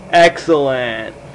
Excellent Sound Effect
Download a high-quality excellent sound effect.
excellent-1.mp3